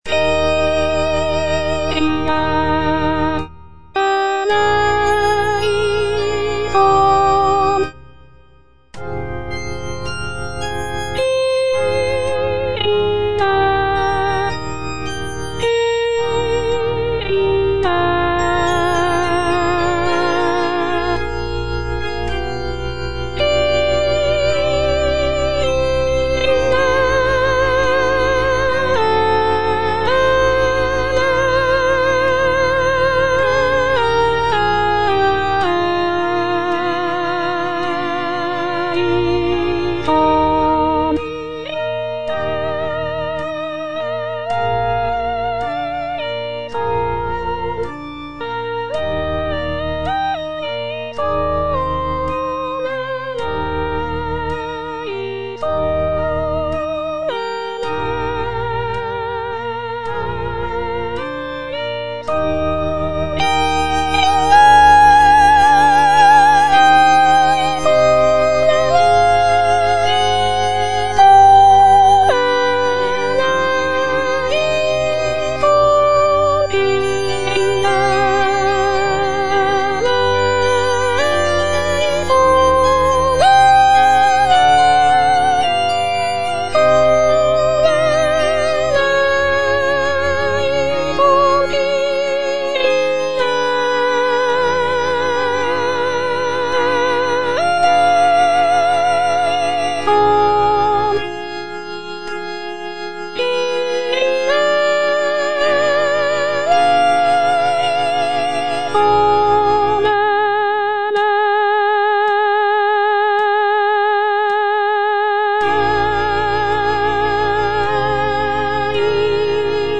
C.M. VON WEBER - MISSA SANCTA NO.1 Kyrie eleison - Soprano (Voice with metronome) Ads stop: auto-stop